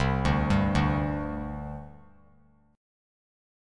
Csus2 Chord